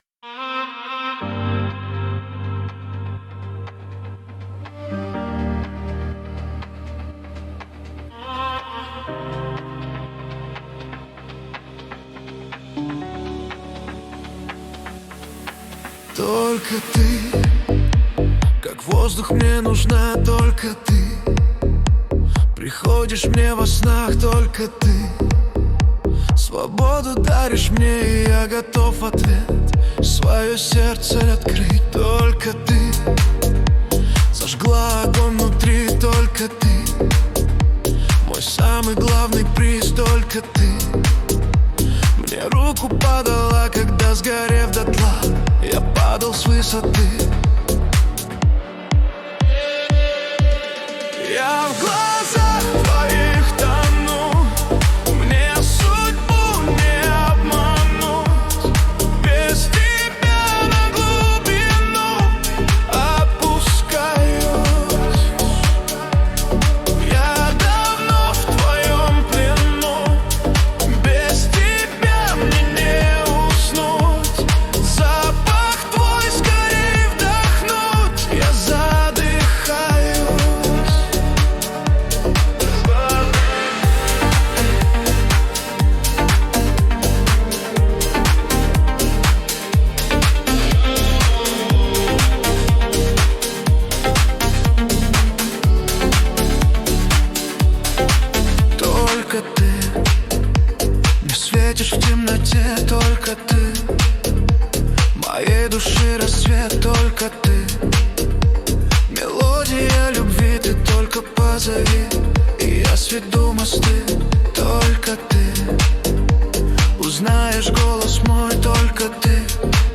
Качество: 320 kbps, stereo
Поп музыка, Русские поп песни